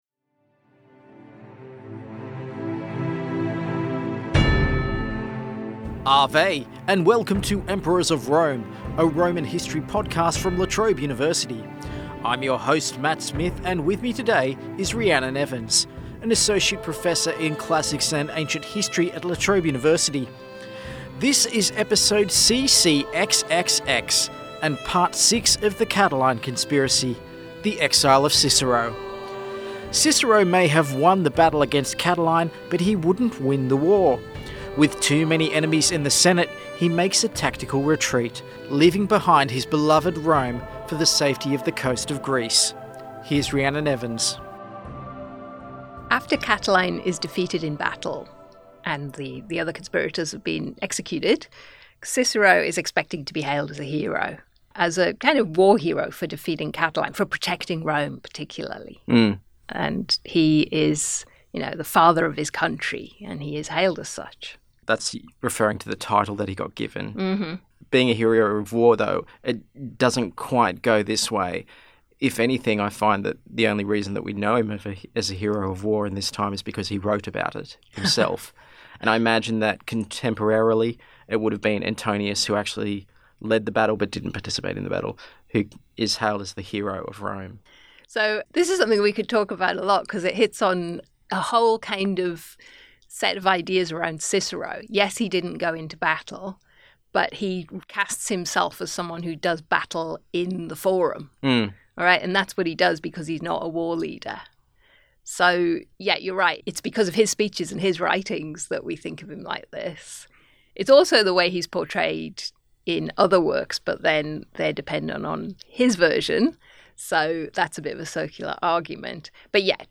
Guest: Associate Professor